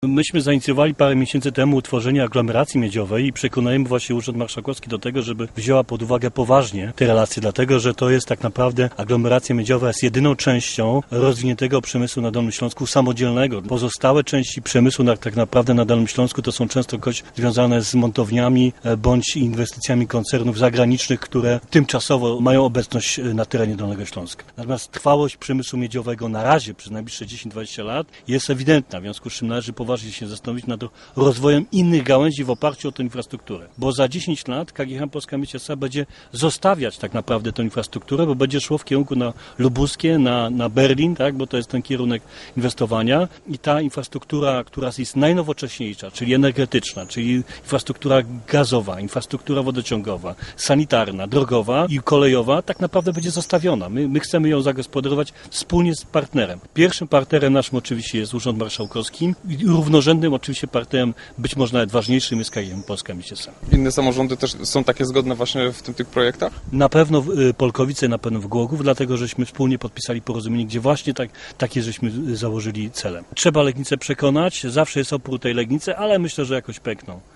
Mówi Robert Raczyński, prezydent Lubina.